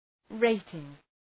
Προφορά
{‘reıtıŋ}